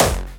Hardstyle Punch
Hey Leute ich hab eine Frage an euch, ich möchte wissen wie man einen Hardstyle Punch macht, und ich finde keine Tutorials dazu. ich habe aus einem Samplepack ein paar gefunden, nur ich will die ja selber machen.